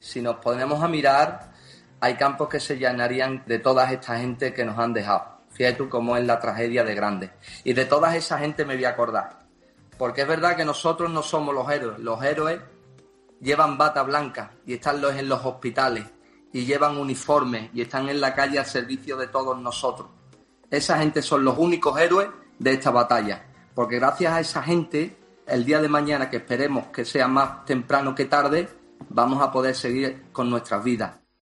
El futbolista del Puerto de Santa María ha conectado desde su casa con El Hormiguero y ha aproechado para lamentar la situación que está atravesando el país.